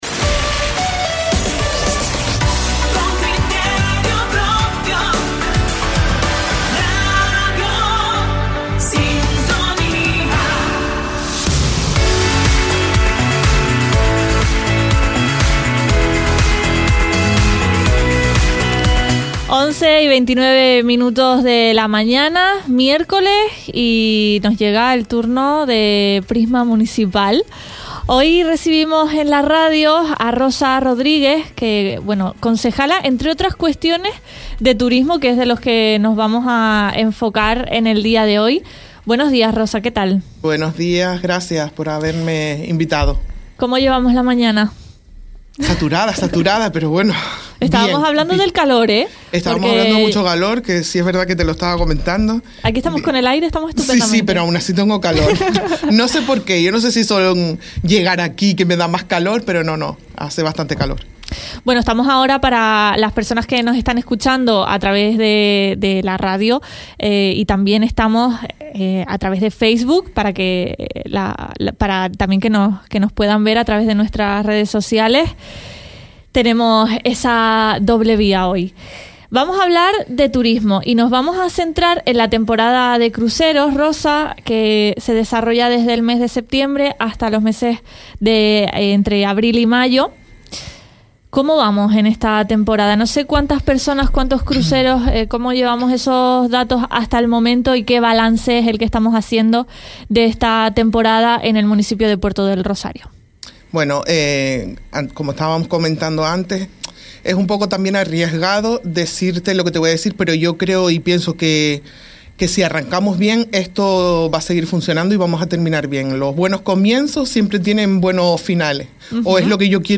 La concejala de Turismo del Ayuntamiento de Puerto del Rosario, Rosa Rodríguez, ha sido quien nos ha acompañado este miércoles en el Espacio de Prisma Municipal en Radio Sintonía. Su intervención se ha centrado en la actividad relacionada con la temporada de cruceros y las iniciativas que se fomentan desde el ayuntamiento.